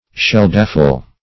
Search Result for " sheldafle" : The Collaborative International Dictionary of English v.0.48: Sheldafle \Sheld"a*fle\, Sheldaple \Sheld"a*ple\, n. [Perhaps for sheld dapple.